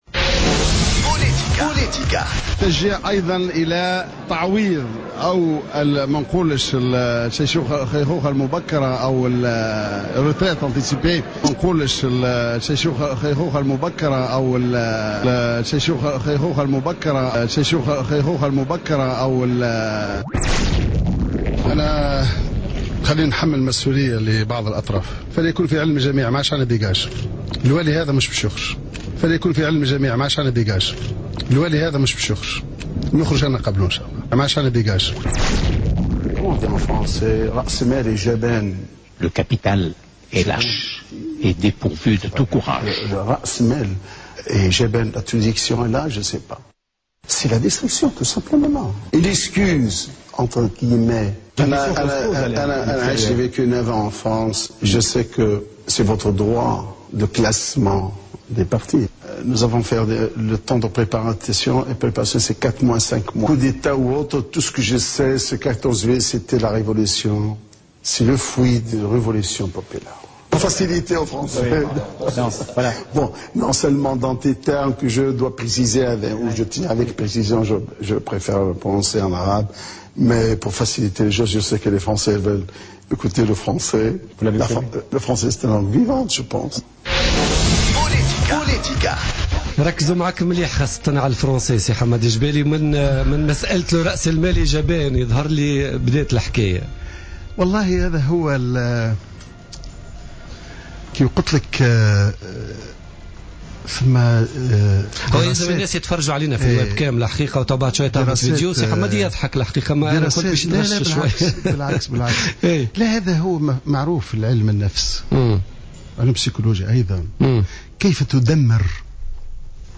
قال حمادي الجبالي، القيادي المستقيل حديثا من حركة النهضة في تصريح للجوهرة أف أم اليوم الاربعاء إن اتهامه من قبل منافسيه وخصومه السياسية بعدم إجادته للغة الفرنسية يأتي في إطار حربهم النفسية التي شنوها ضدها وضد كل من جاء بعد الثورة التي تكاد تصبح تهمة على حد تعبيره.